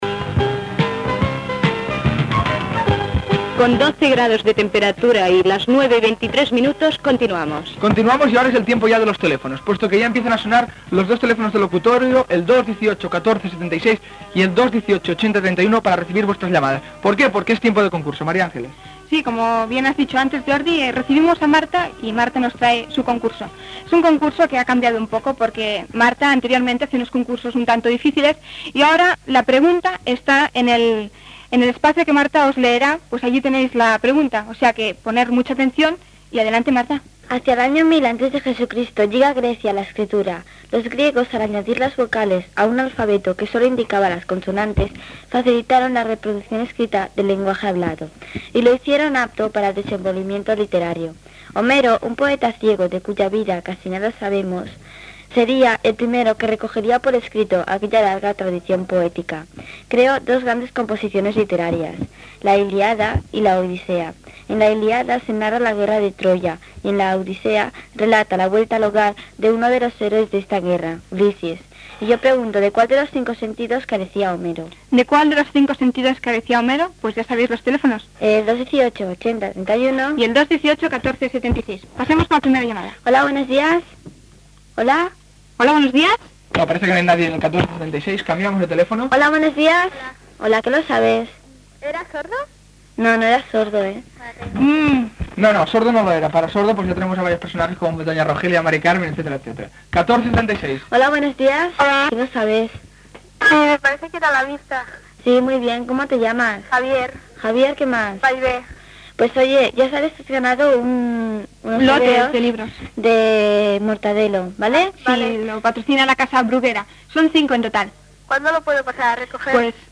Hora, pregunta sobre Homer i trucades dels oients que participen en el concurs.
Infantil-juvenil